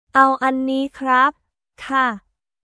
オー アン ニー クラップ／カー